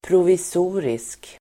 Uttal: [provis'o:risk]